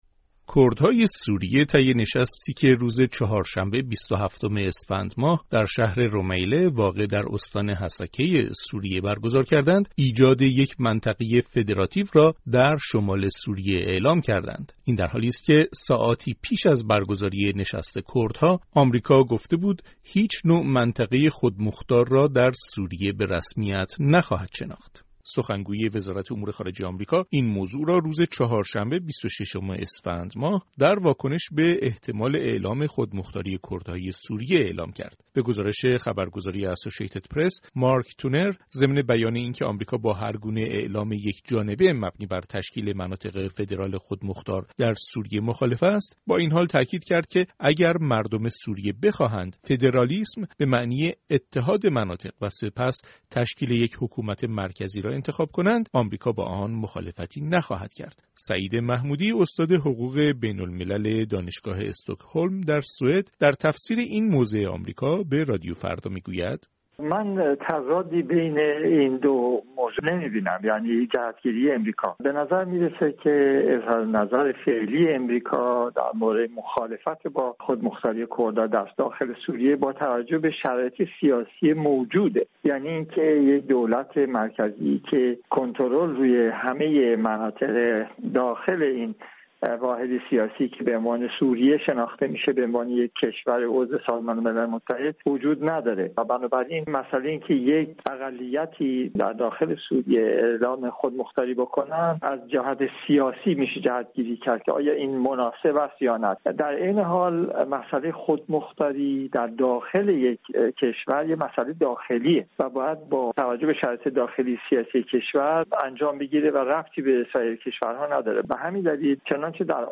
گزارش رادیویی